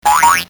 SFX叮叮音效下载
这是一个免费素材，欢迎下载；音效素材为叮叮， 格式为 mp3，大小1 MB，源文件无水印干扰，欢迎使用国外素材网。